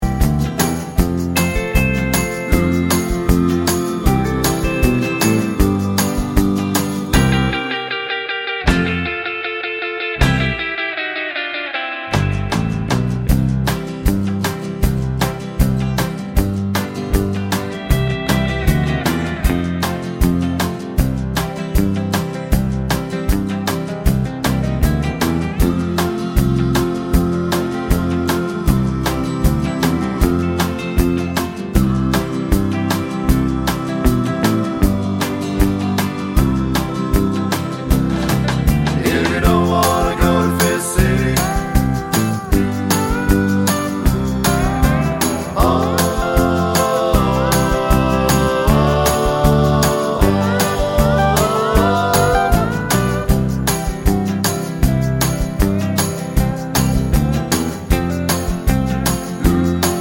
no Backing Vocals Country (Female) 2:10 Buy £1.50